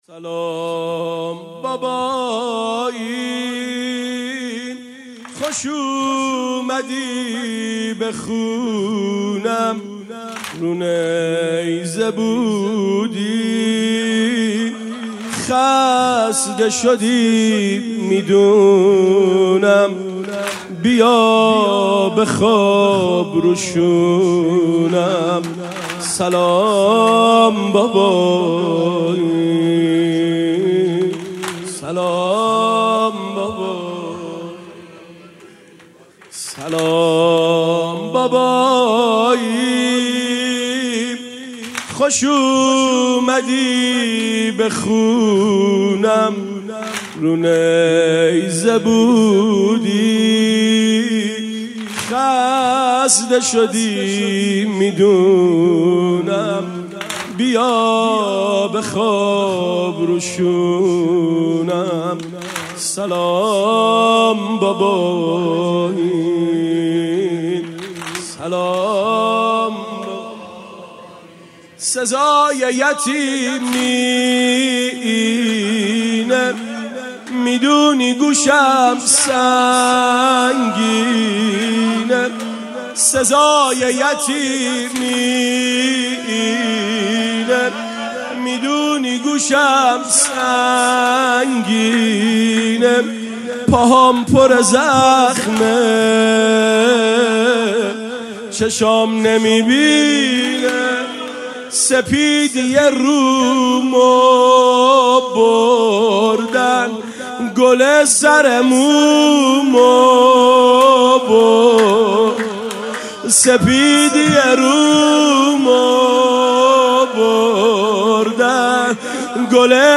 شب سوم محرم ۱۴۴۴